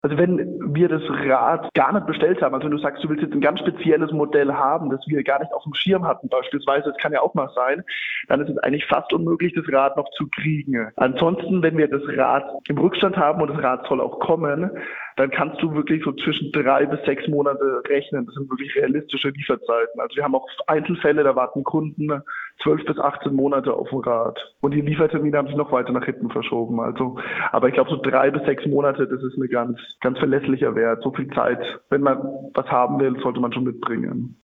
Interview über die Fahrradsaison 2022 - PRIMATON